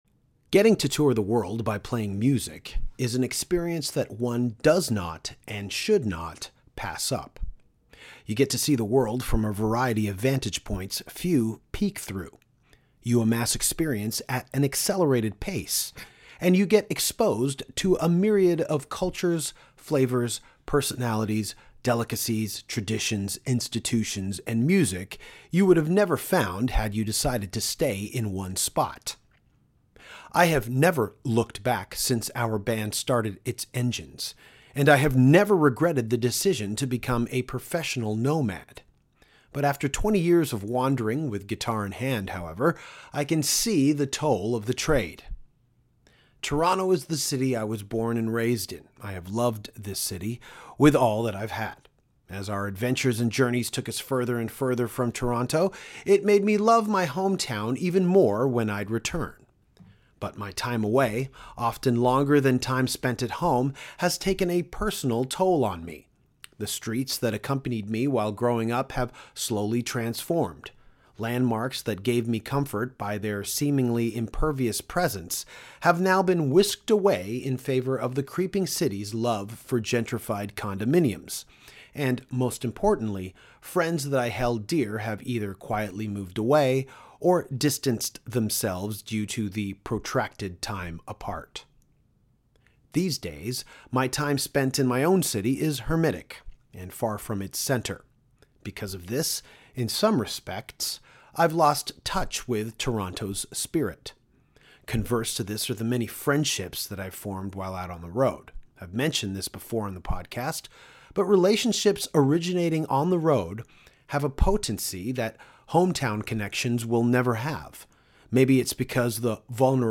Danko caught up with Donald Tardy, drummer of Florida Death Metal legends, Obituary, at SpeedFest this past fall in Eindhoven, Holland.